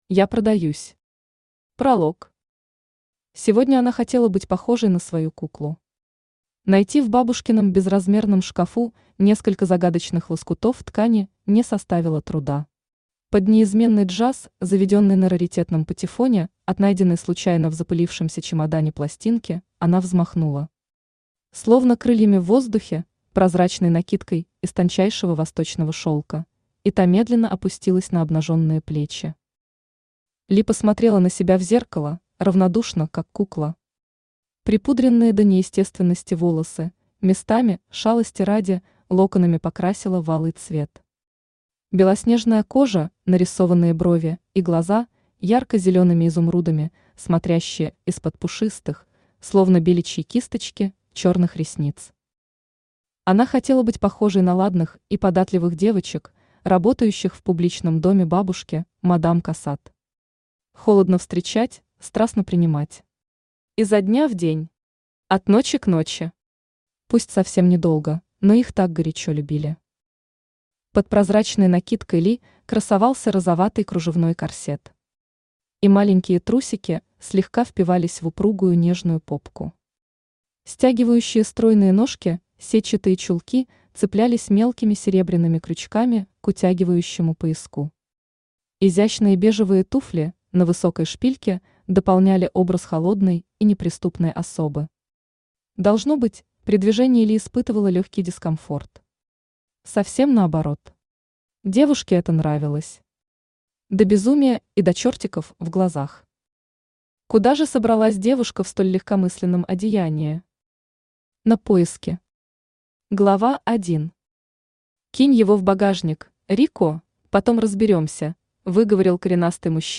Аудиокнига Я продаюсь. Ты меня купил | Библиотека аудиокниг
Ты меня купил Автор Ася Юрьевна Вакина Читает аудиокнигу Авточтец ЛитРес.